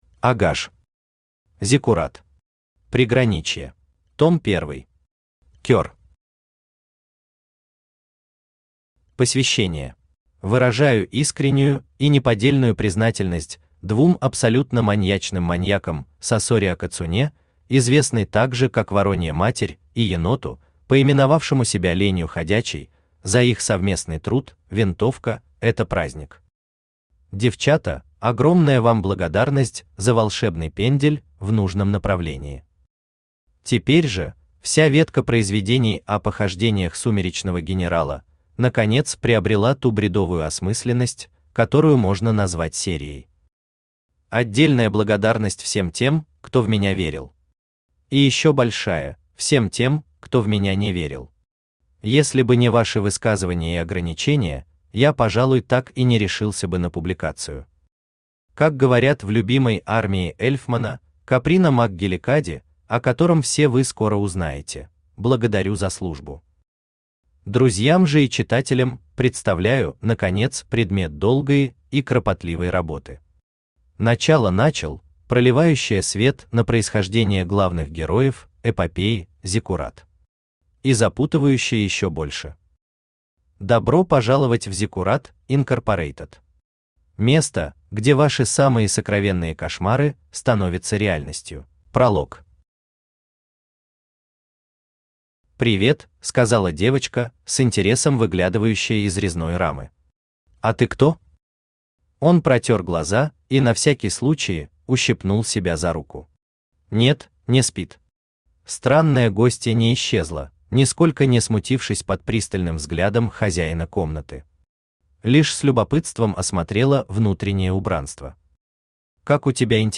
Аудиокнига Зиккурат. Преграничье | Библиотека аудиокниг
Преграничье Автор А Р ГАЖ Читает аудиокнигу Авточтец ЛитРес.